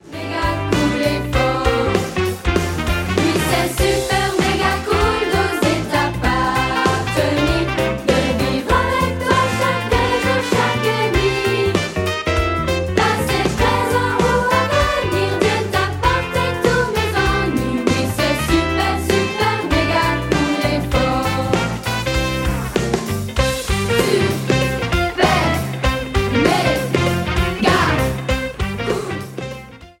Comédie musicale